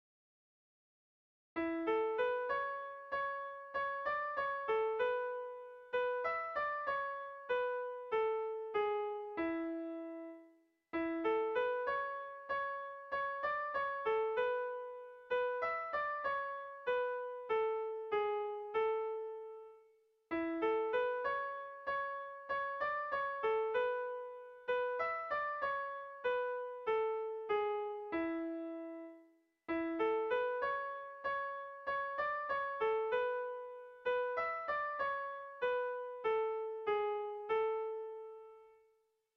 Sentimenduzkoa
Zortziko handia (hg) / Lau puntuko handia (ip)
A1A2A1A2